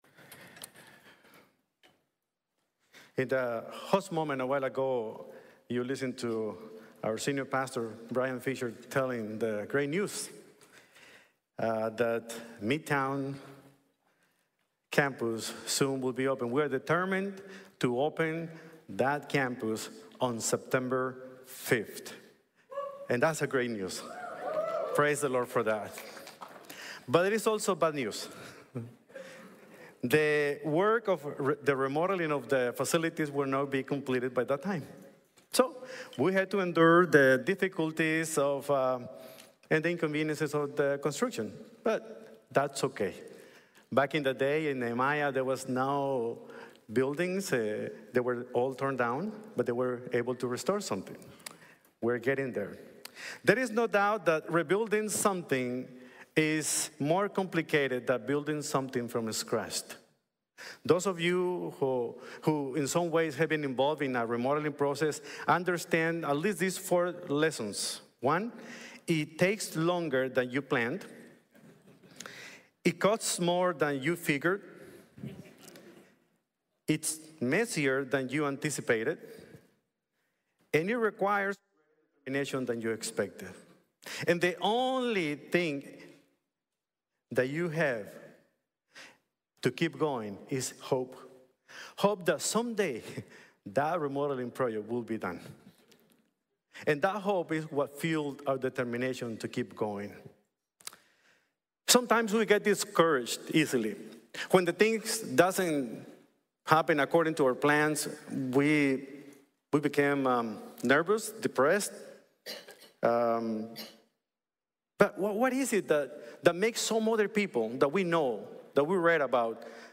Nehemías - Cuidar lo suficiente para actuar | Sermón | Iglesia Bíblica de la Gracia